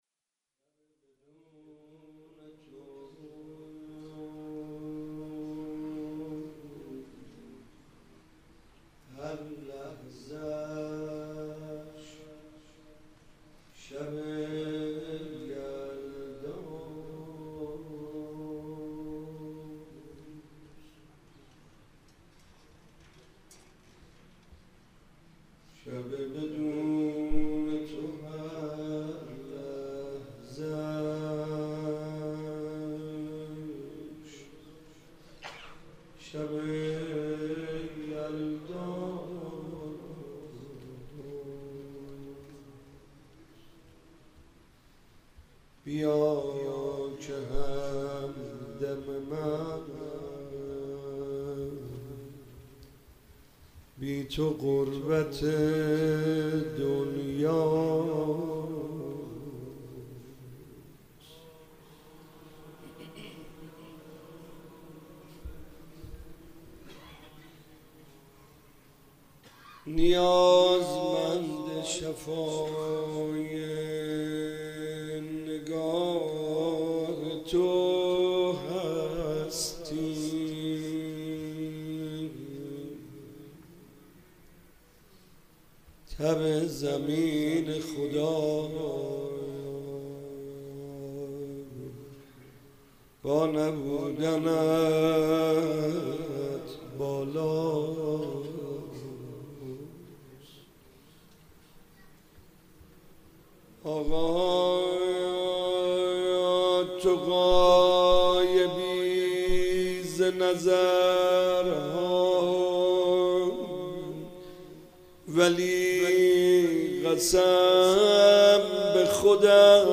مناجات امام زمان
با اینکه پیکر پسرش بوریا شود روضه محمود کریمی